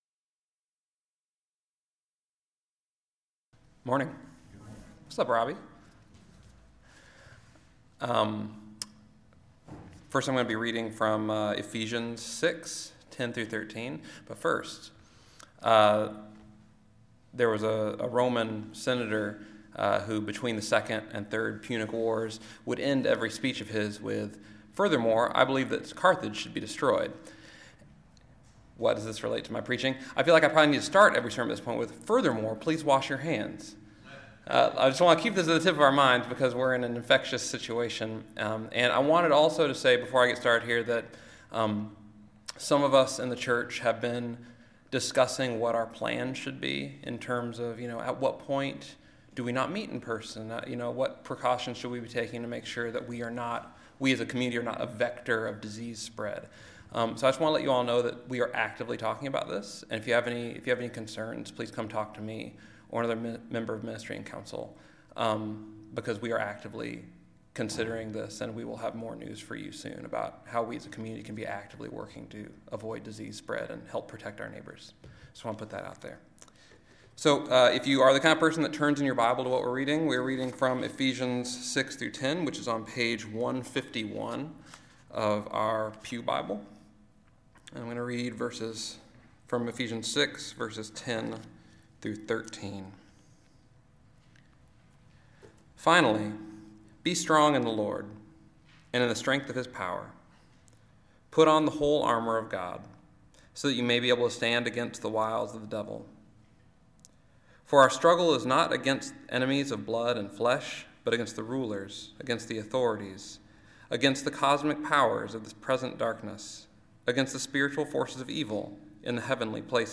Listen to the most recent message from Sunday worship at Berkeley Friends Church, “Gospel of Hope.”